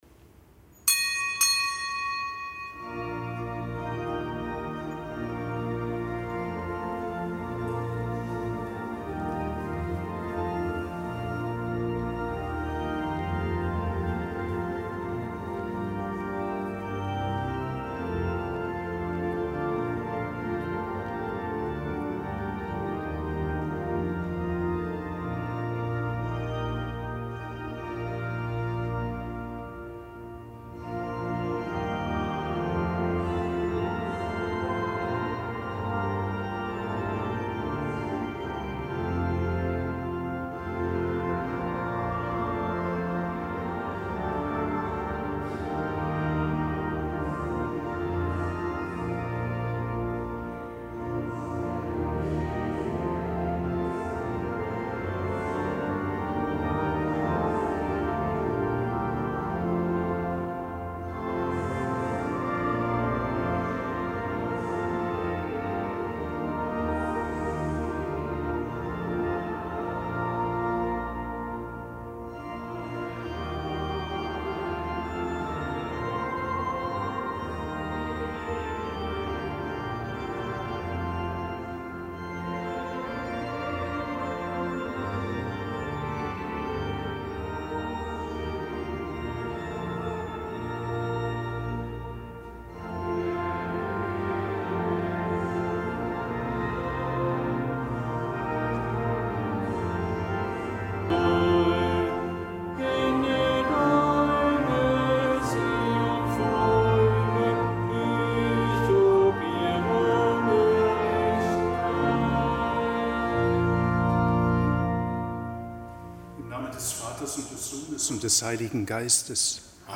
Kapitelsmesse am Mittwoch der fünfzehnten Woche im Jahreskreis